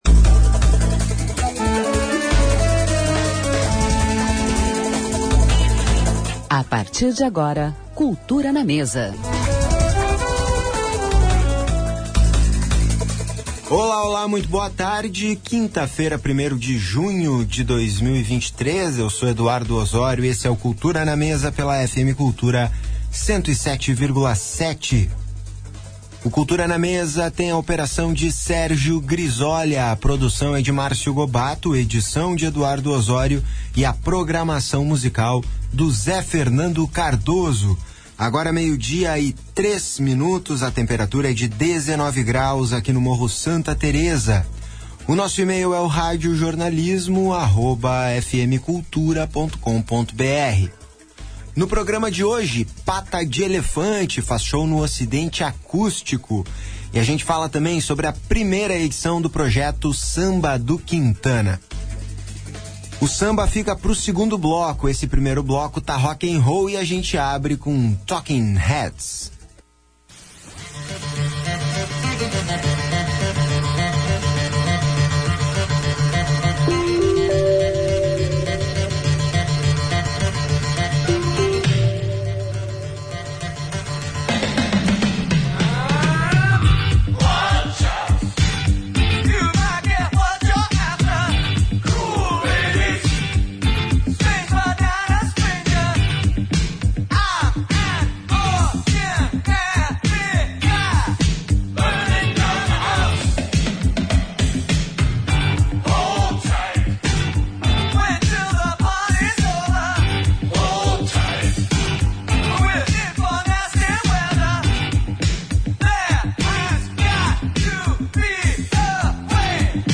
Entrevista e música ao vivo com o músico